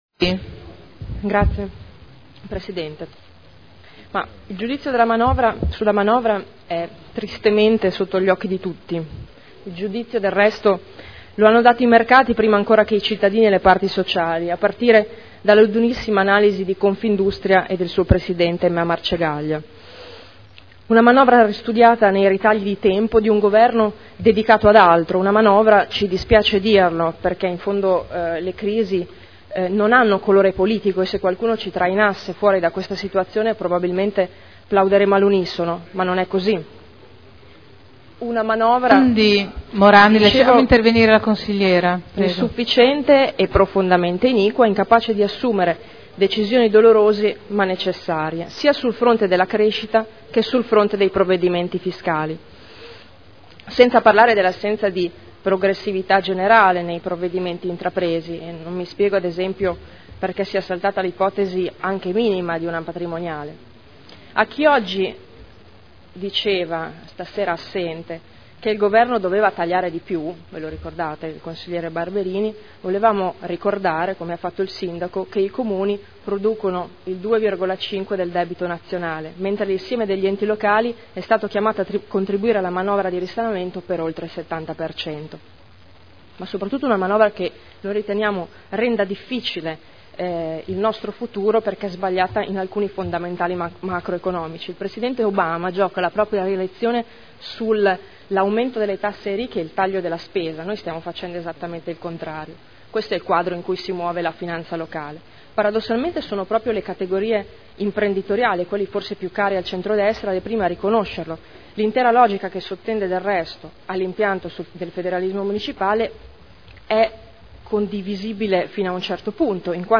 Seduta del 26 settembre 2011 Ricadute della manovra del Governo sul Bilancio del Comune di Modena – Dibattito